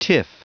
Prononciation du mot tiff en anglais (fichier audio)
Prononciation du mot : tiff